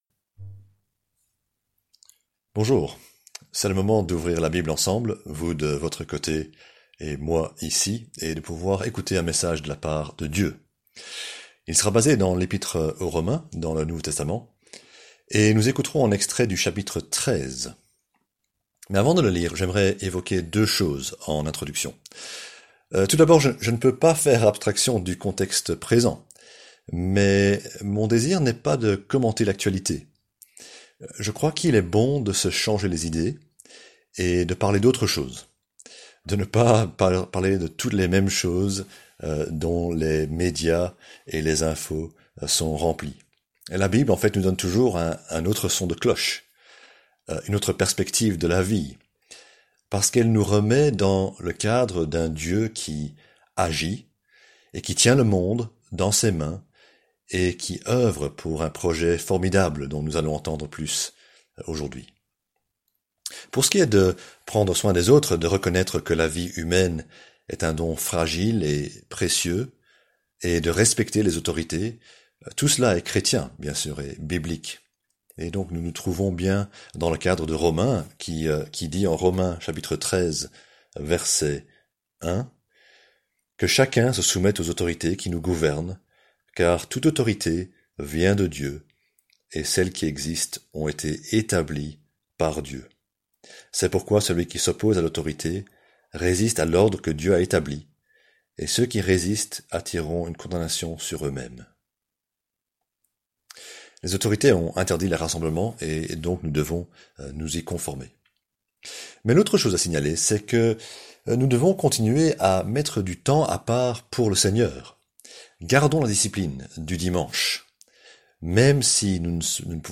Prédication en ligne du dimanche 15/03/2020. L'amour de notre prochain et la sainteté (Romains 13.8-14)